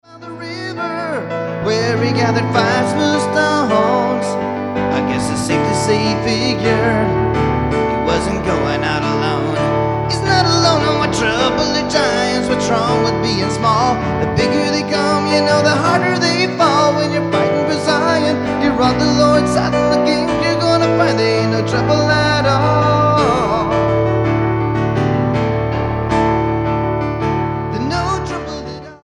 STYLE: Roots/Acoustic
Live